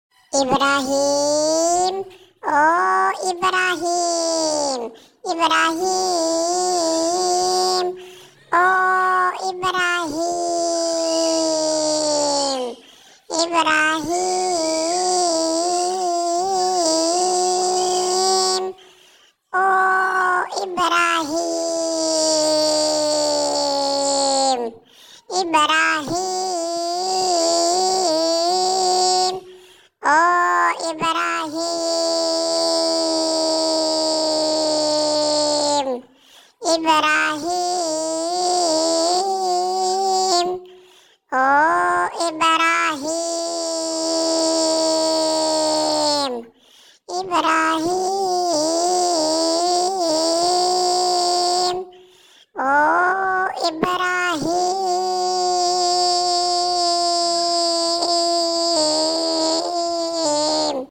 Goat 🐐 calling funny name sound effects free download